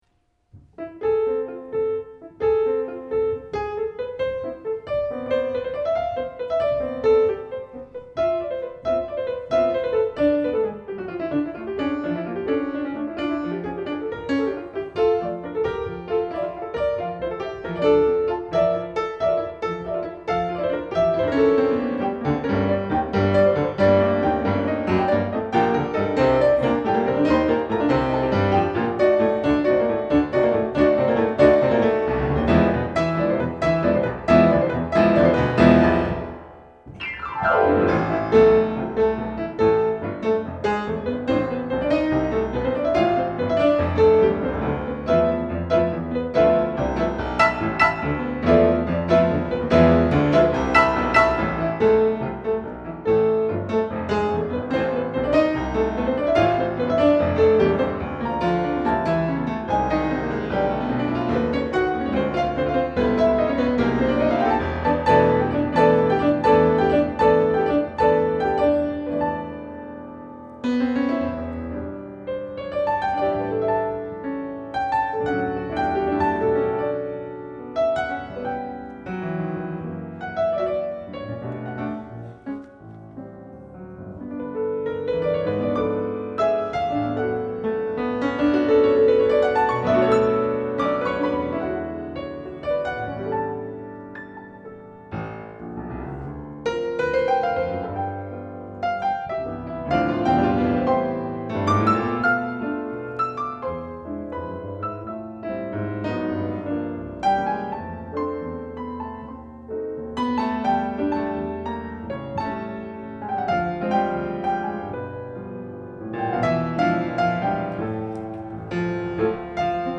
virtuosic two-piano arrangements
Live at Gilmore Festival